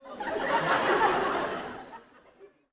Audience Laughing-05.wav